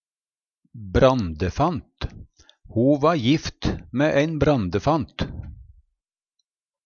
brandefant - Numedalsmål (en-US)